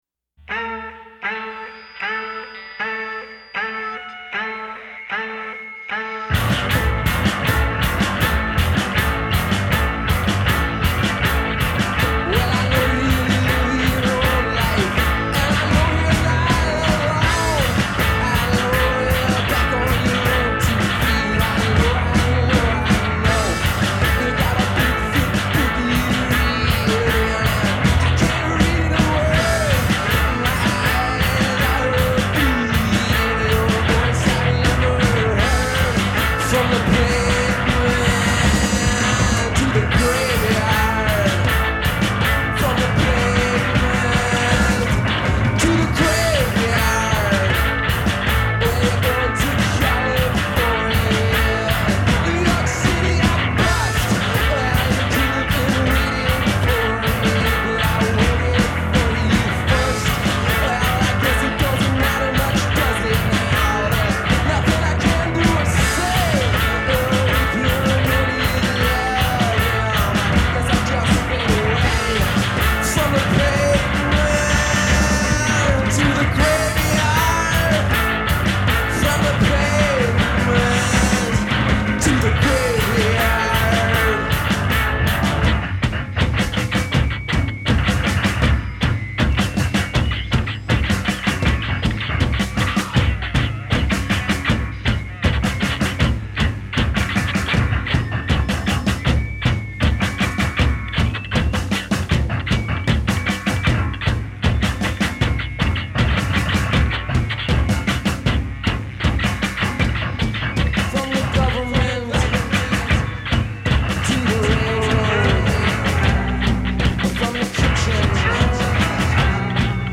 with a skronking, noisy mess of a song entitled